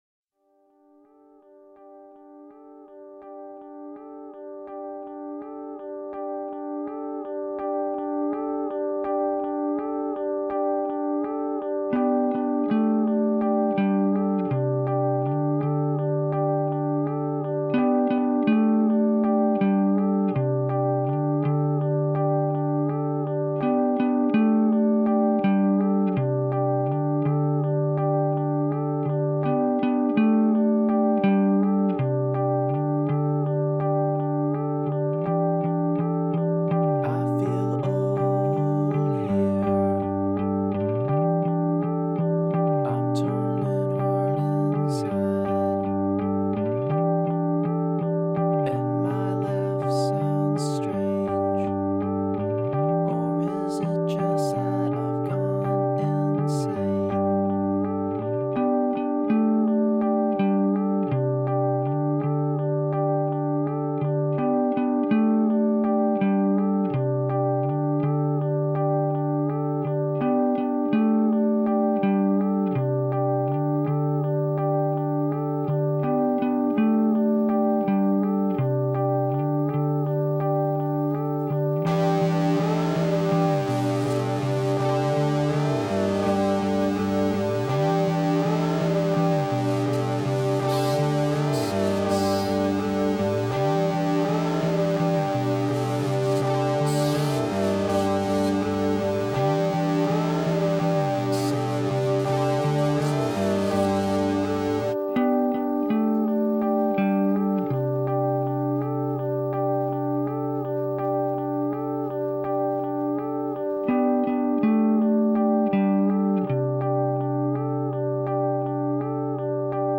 We need a very mellow Song of the Day this morning.
This quiet, lovely, downer
Category: Alternative, Song of the Day